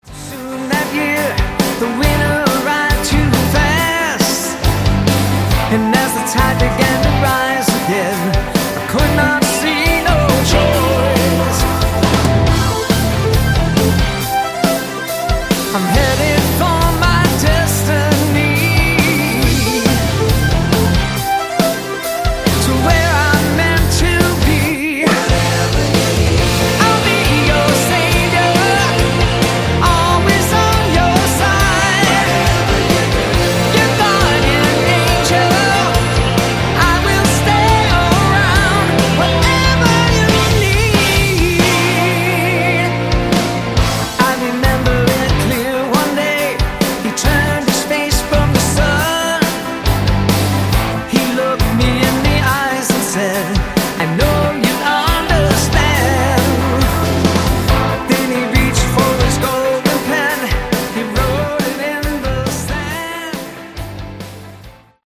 Category: AOR
bass guitar, guitars, keyboards, background vocals